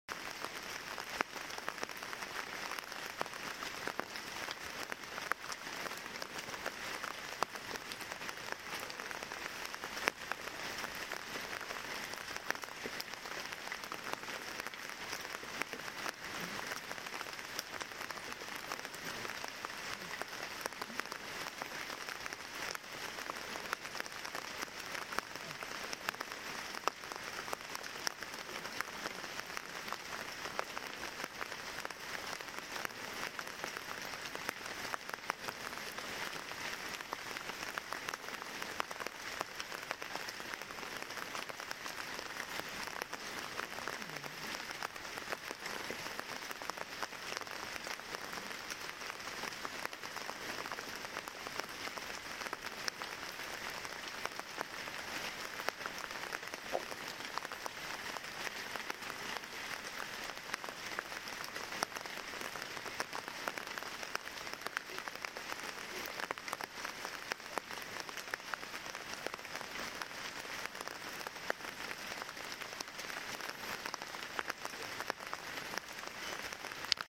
A remote wooden cabin tucked sound effects free download
A remote wooden cabin tucked deep in the Austrian Alps, surrounded by misty pine forests and echoing raindrops. The soft patter of rain on the roof and distant wind in the trees creates the perfect atmosphere for rest, reflection, and deep sleep.